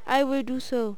horseman_ack5.wav